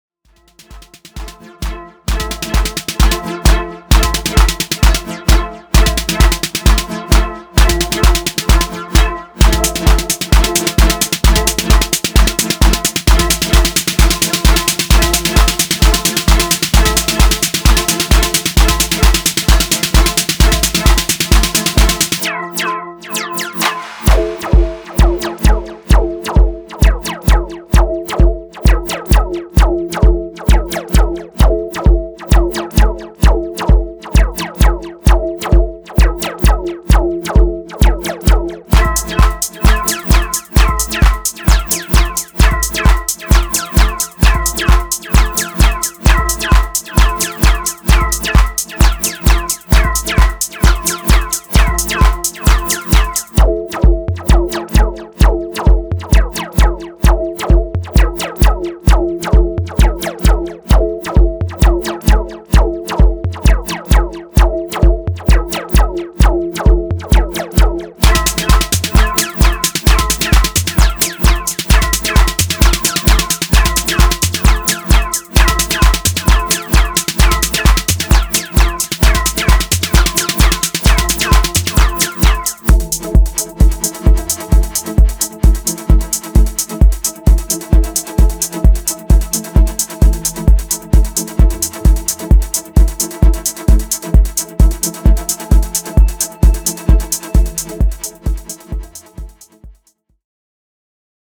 analogue infused jack trax
House Acid